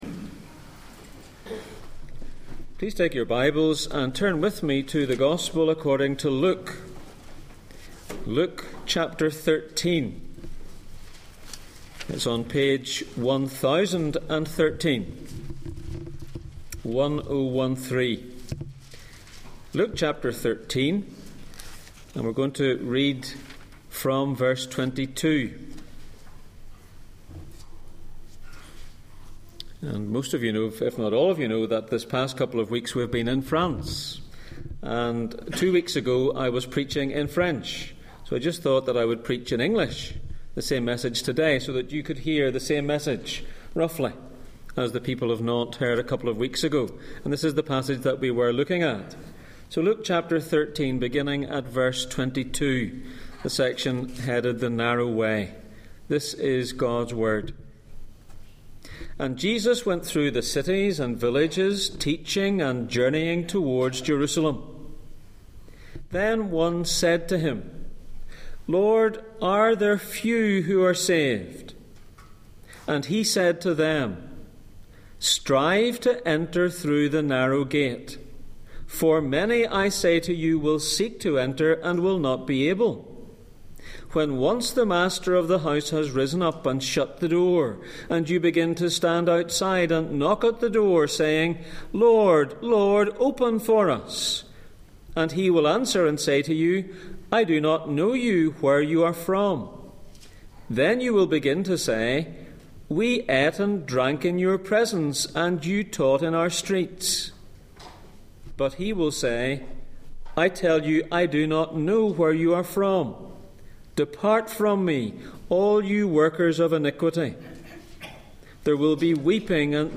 Heaven and hell Passage: Luke 13:22-30 Service Type: Sunday Morning %todo_render% « How to Listen to Preaching The LORD